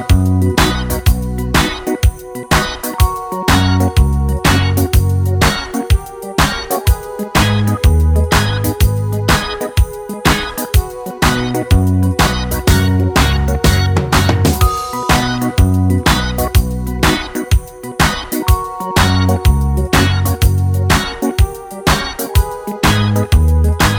no Backing Vocals Reggae 3:45 Buy £1.50